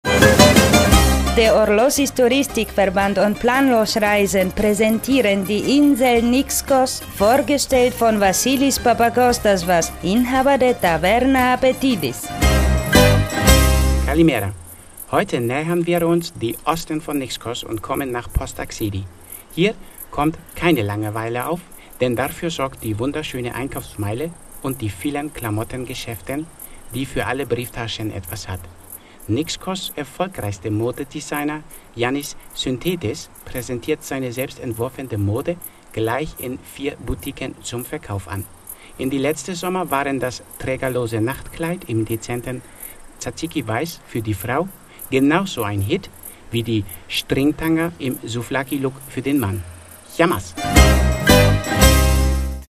INSEL NIXKOS; Radiocomedy